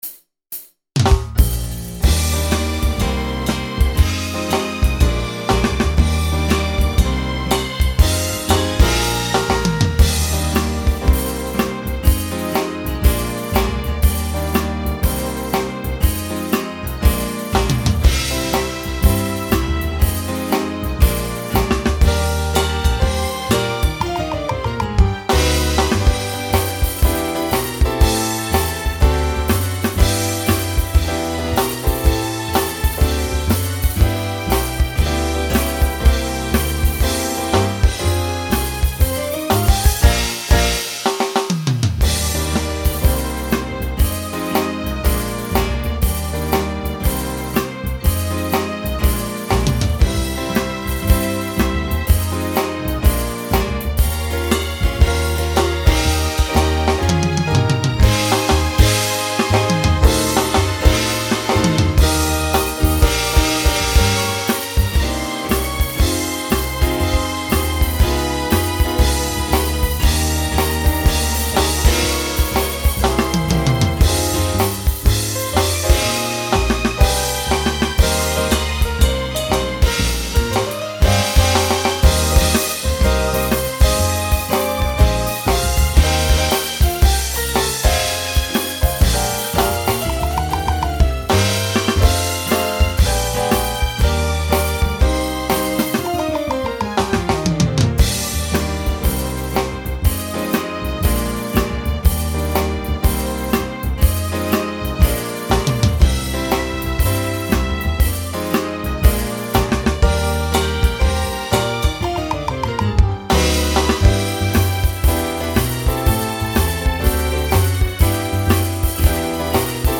Voicing SATB Instrumental combo Genre Broadway/Film
Mid-tempo